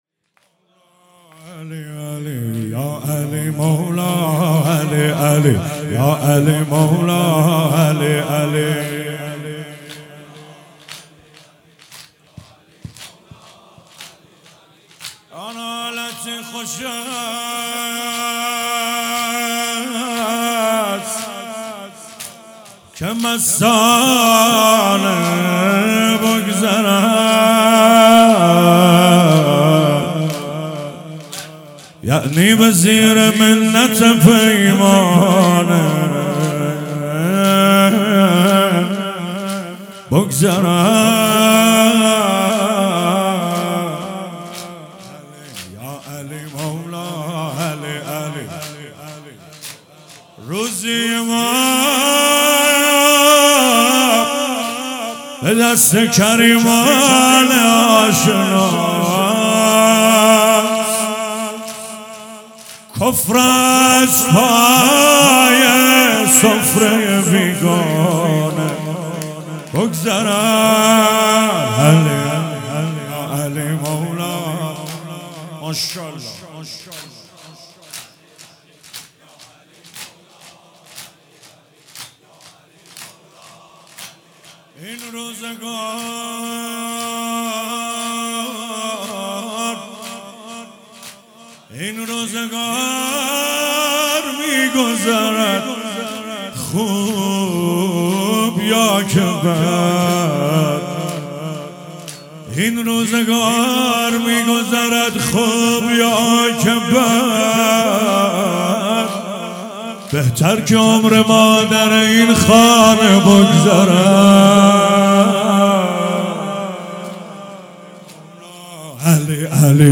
فاطمیه 97